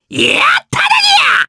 Lakrak-Vox_Happy4_jp.wav